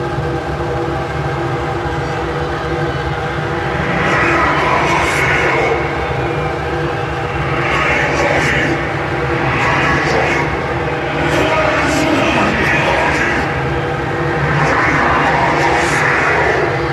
На этой странице представлены таинственные звуки, записанные в глубинах скважин.
Звук жуткой глубины: Скважина, из которой слышались звуки Ада (обработанная запись)